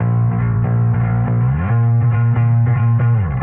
描述：electric bass
标签： bass electric
声道立体声